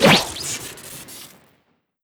blast_dodge.wav